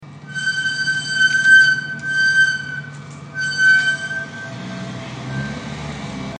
Brakes Squeal